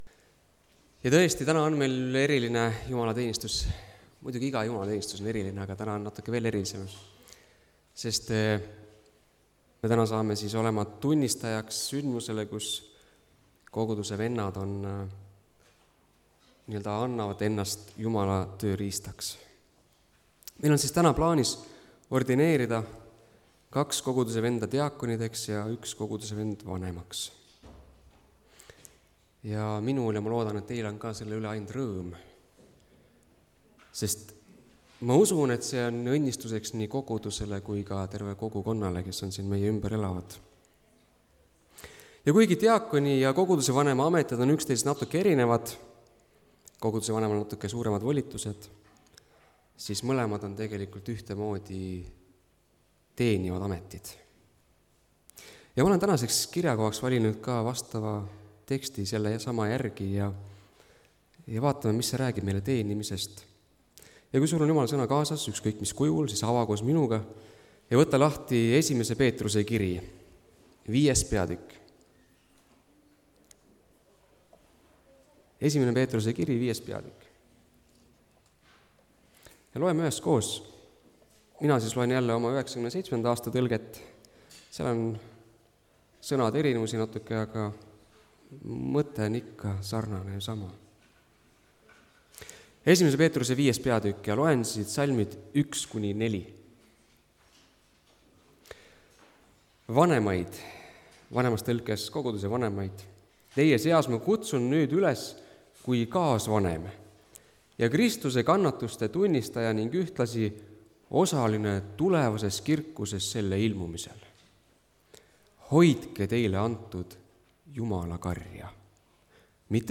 (Tallinnas)
Jutlused